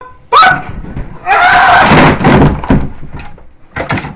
falling scream
Download falling scream Sound effect Button free on sound buttons.